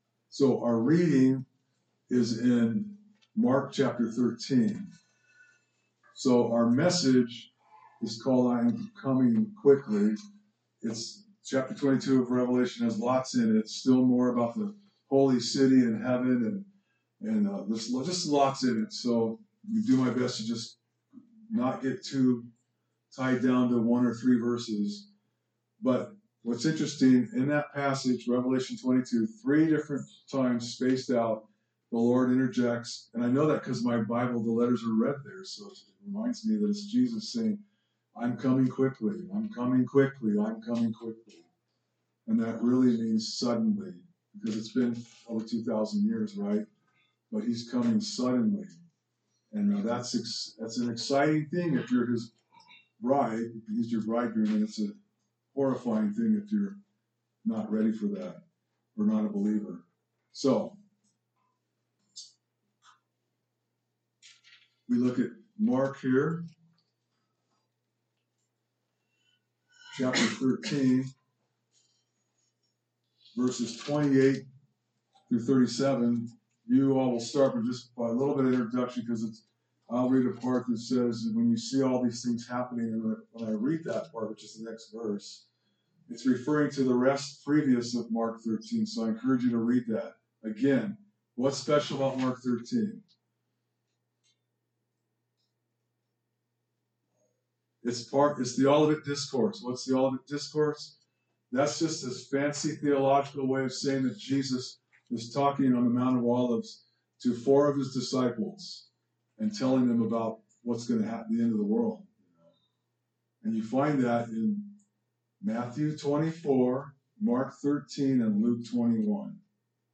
Sermons - Calvary Chapel Ames